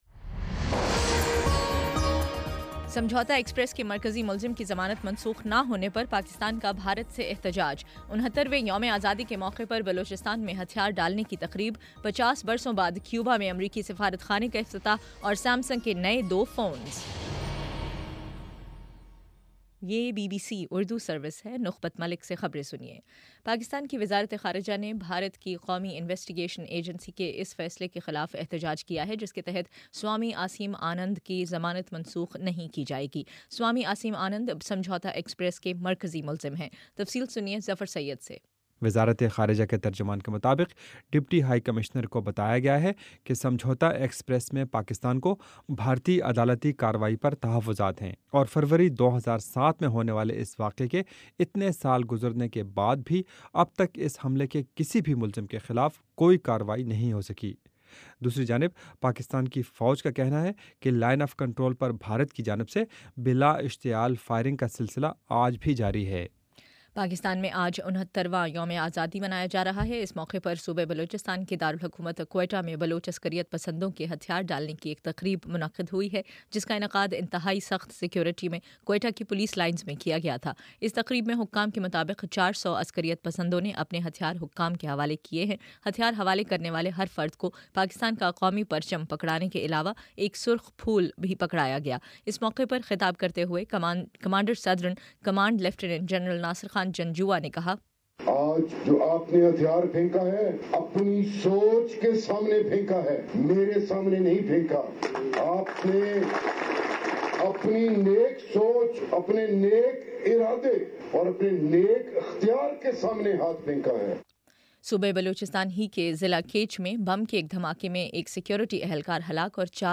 اگست 14: شام چھ بجے کا نیوز بُلیٹن